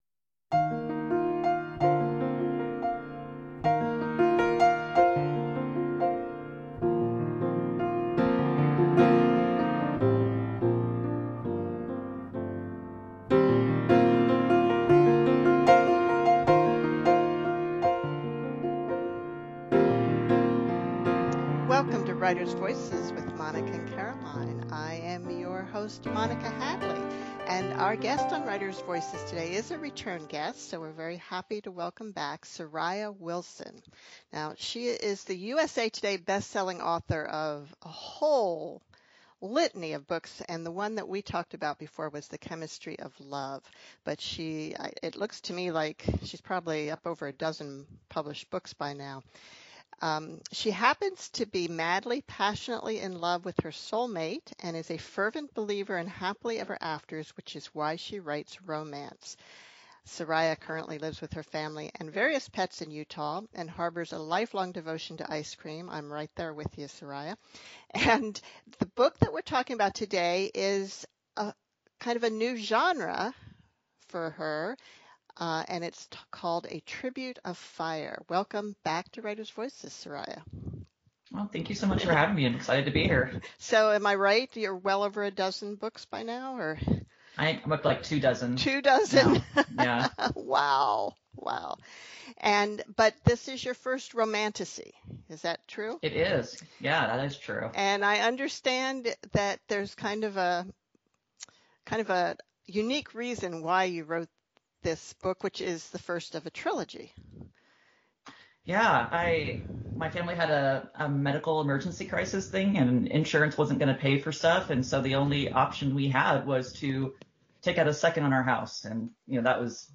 USA Today bestselling author, Sariah Wilson, visits Writer’s Voices to discuss her debut romantasy, A Tribute of Fire .